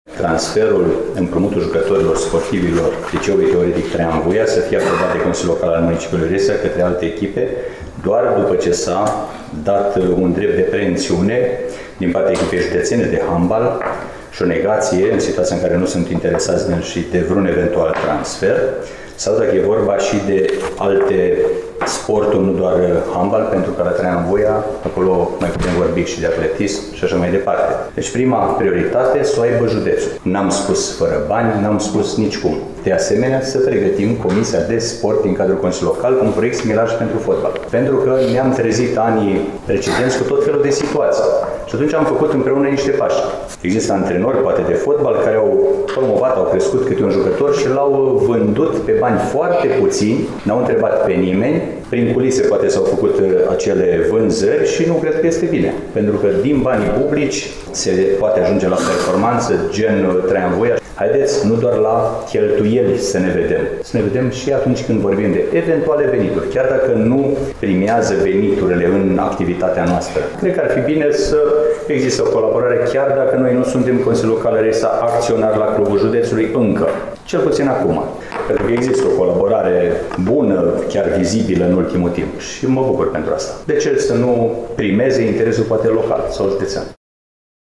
traian-vuia-juniori1-2012Membrii Consiliului Local Reşiţa au luat în discuţie în şedinţa ordinară de marţi, 27 august situaţia transferurilor sportivilor de la unităţile de învăţământ finanţate de primăria municipiului de pe Bârzava.
Ascultaţi mai jos propunerea primarului Mihai Stepanescu: